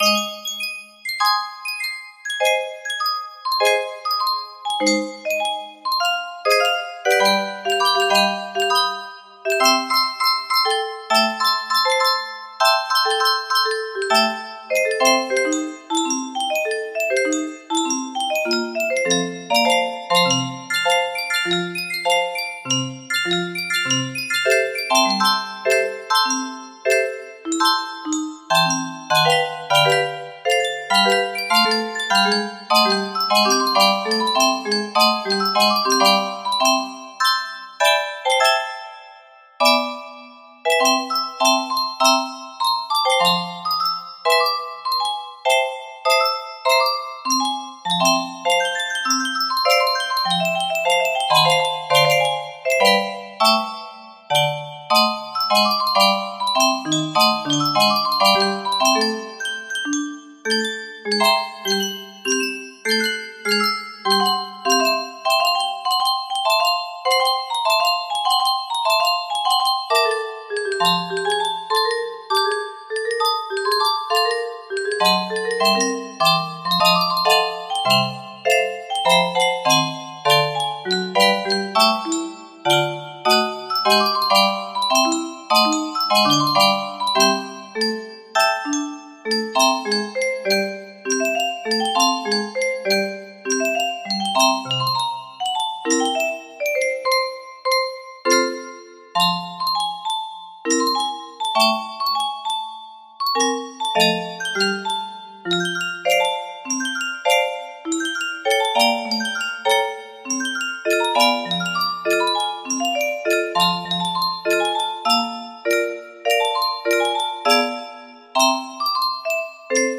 Jelly Roll - Jelly Roll Blues music box melody